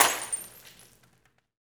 Index of /90_sSampleCDs/Roland L-CD701/PRC_Guns & Glass/PRC_Glass Tuned